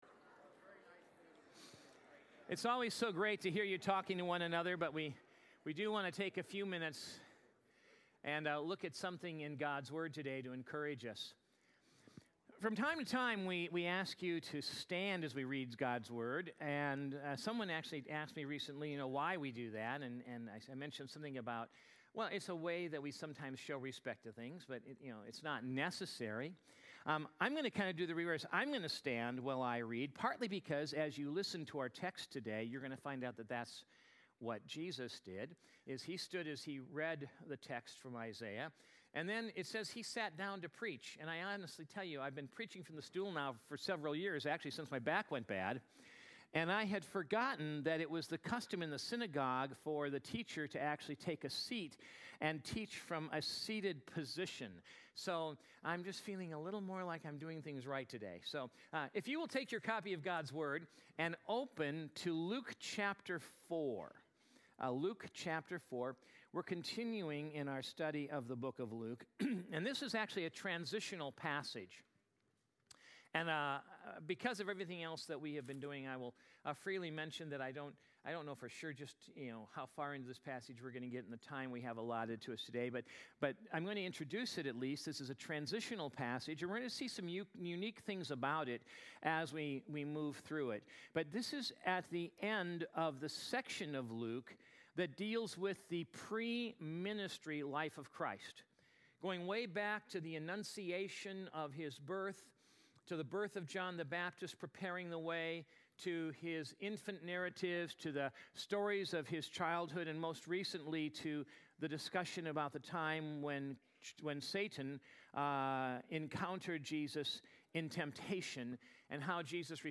Sun_AM_Sermon_3_2_20.mp3